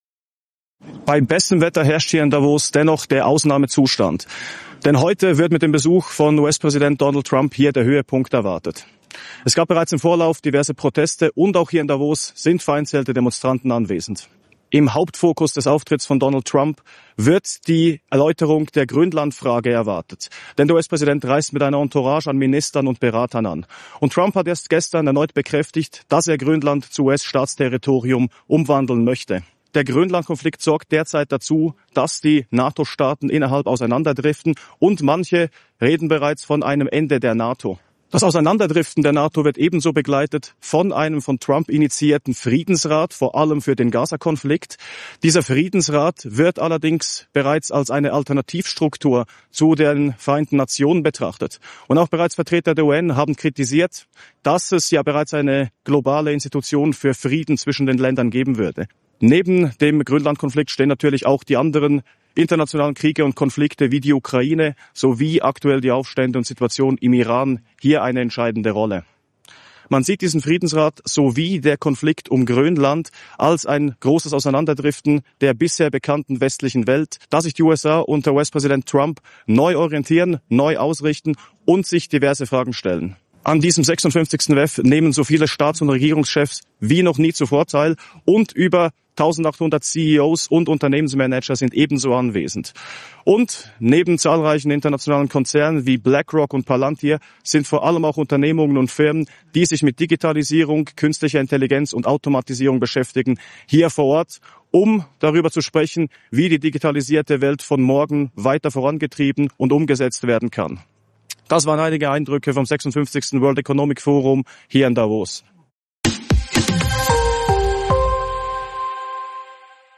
ordnet die Lage in seinem Kommentar direkt aus Davos ein.